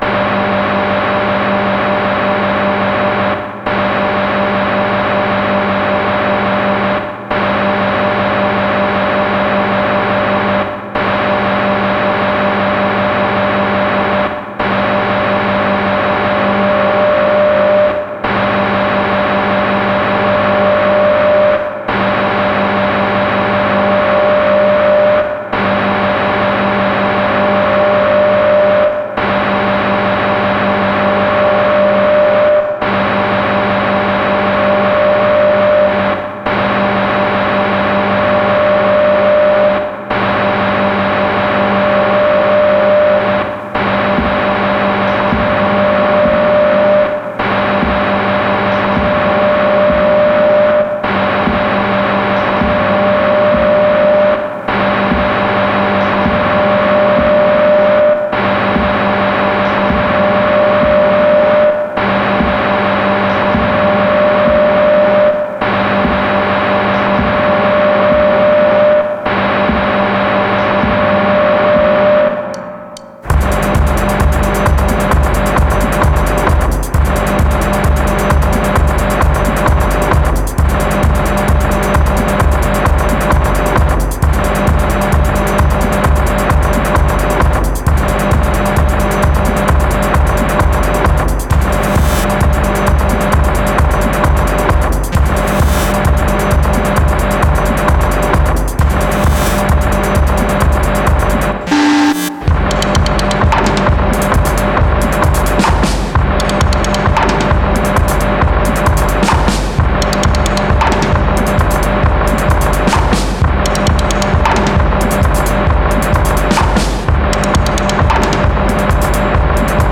mastering his acoustic drumset